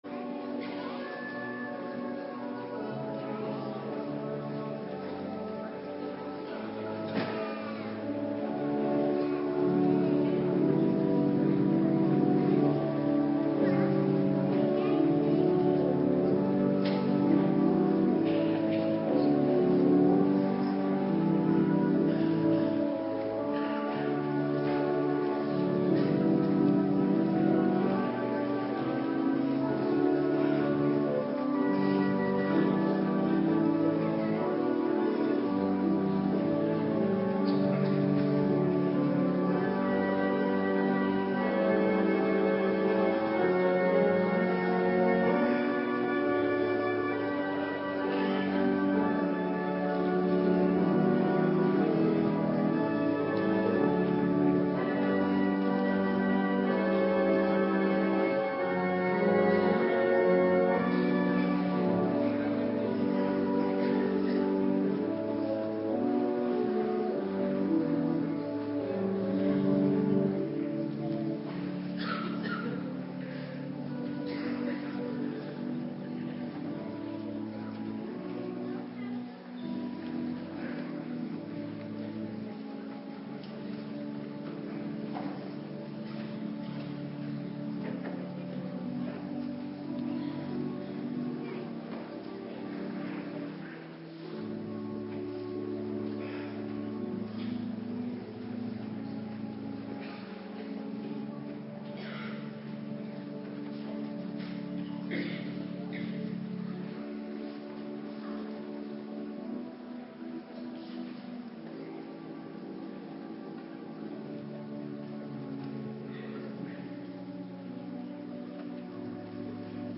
Morgendienst